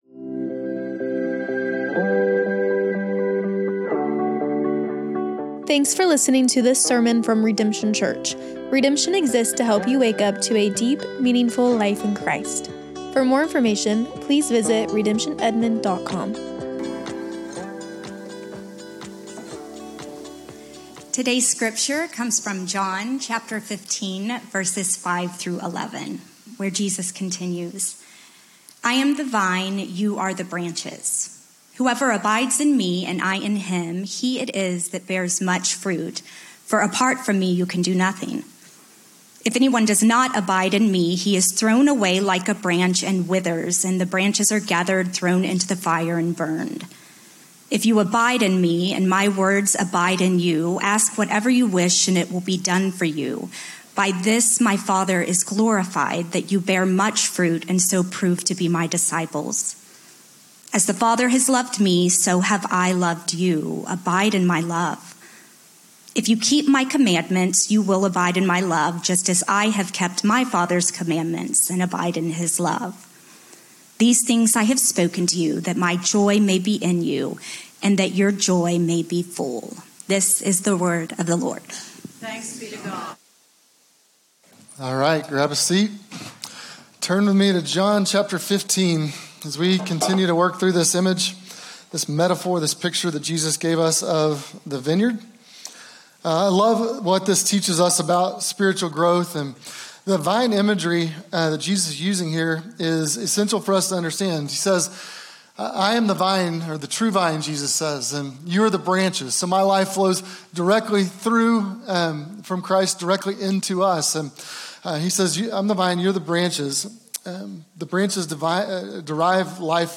SERMONS - Redemption Church